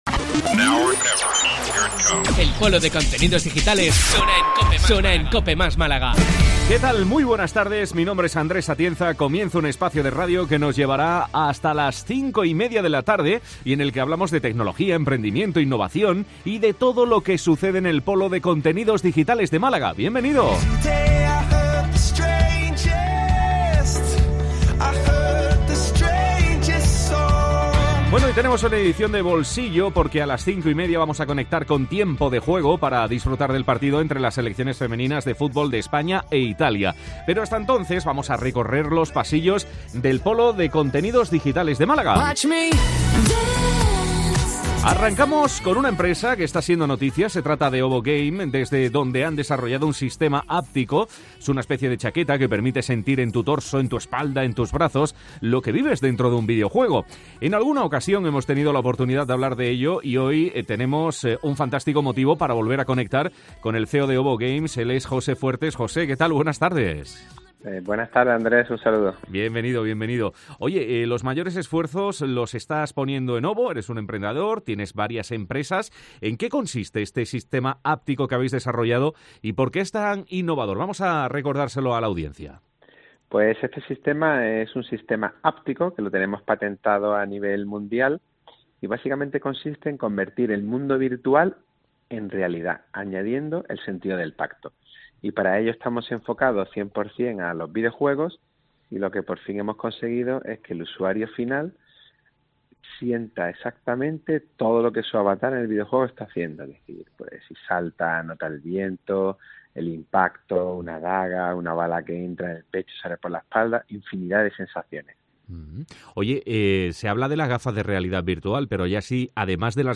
AUDIO: Disfruta de un espacio de radio en el que dedicamos muchos minutos a hablar de lo que sucede en el Polo de Contenidos Digitales de Málaga.